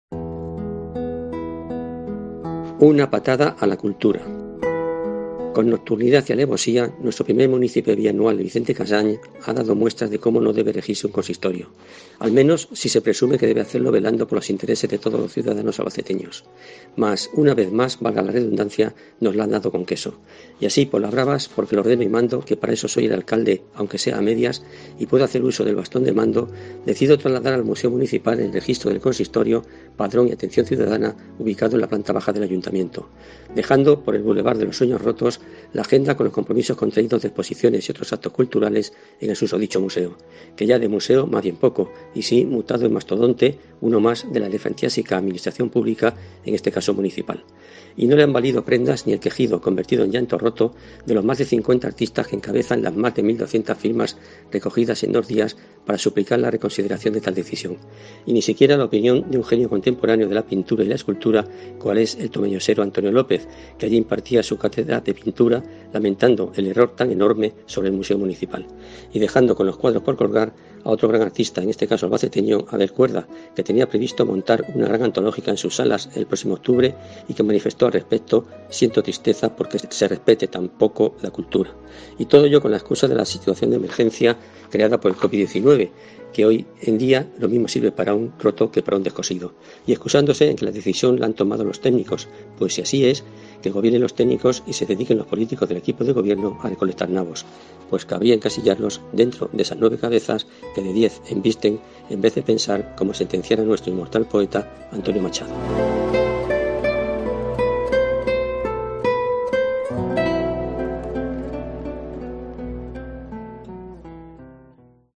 Opinión Albacete